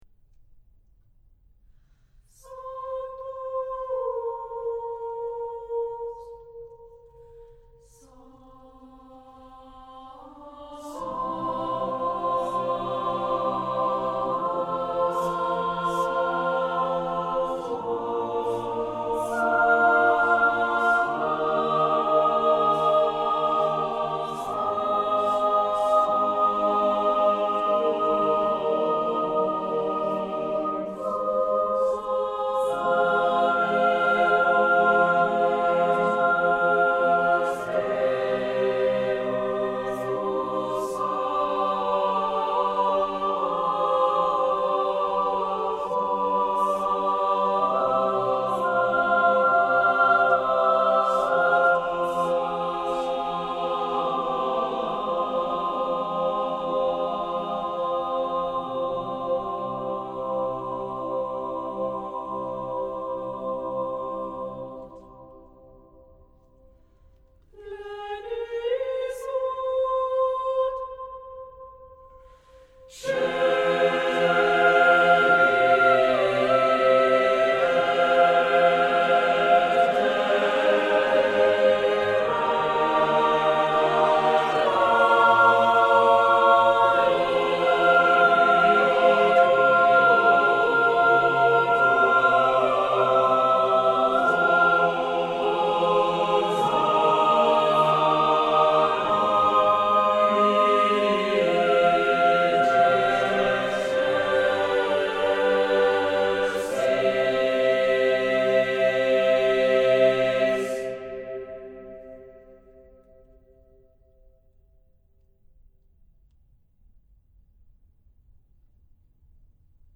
Music Category:      Early Music